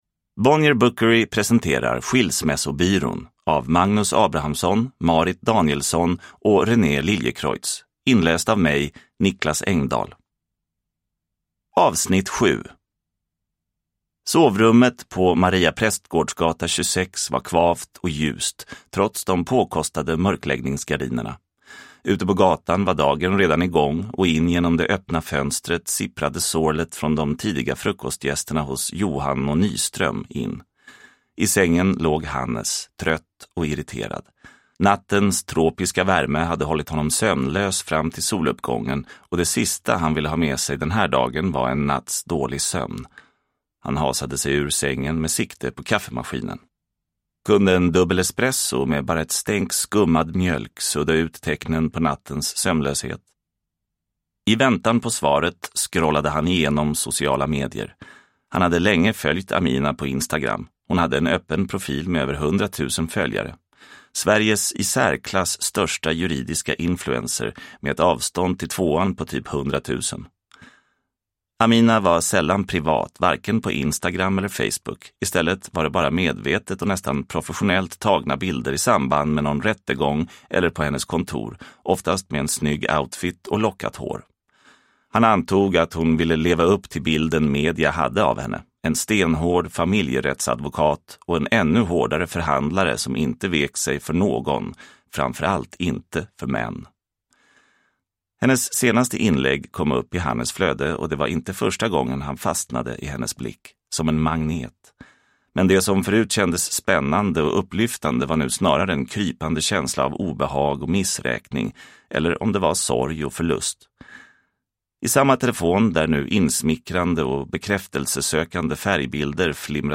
Skilsmässobyrån. S1E7 – Ljudbok – Laddas ner